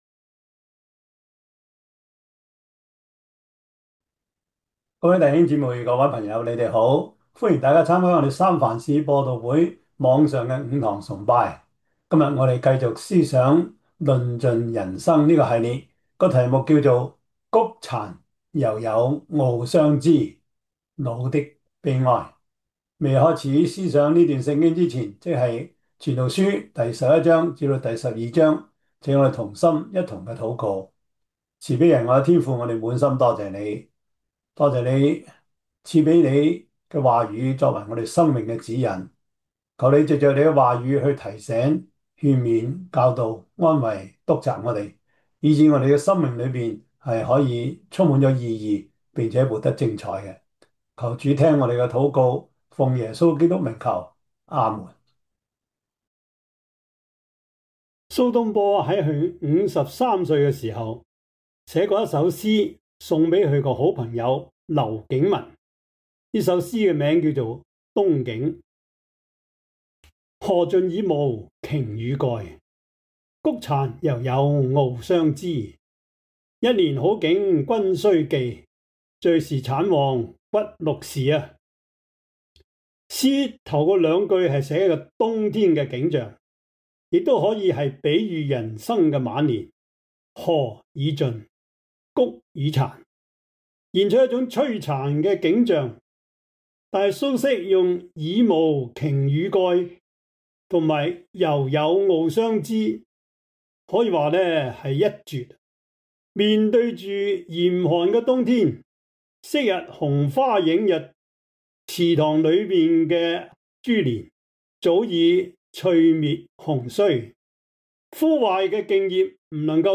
2026 主日崇拜 Passage: 傳道書 11-12 Service Type: 主日崇拜 傳道書 11-12 Chinese Union Version